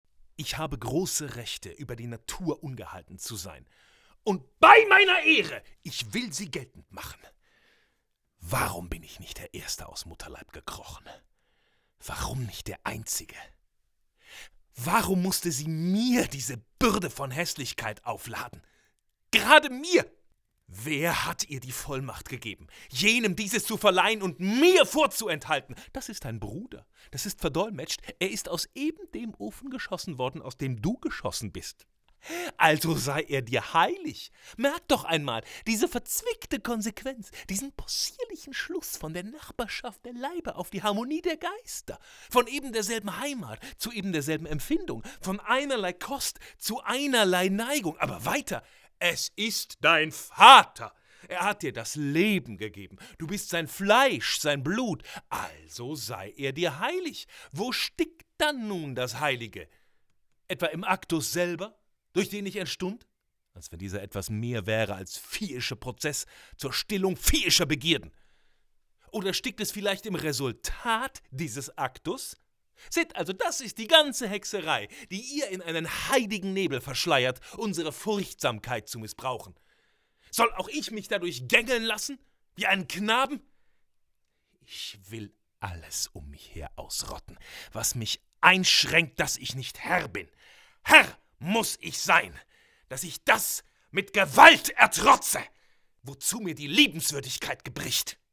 sehr variabel, dunkel, sonor, souverän
Mittel minus (25-45)
Commercial (Werbung)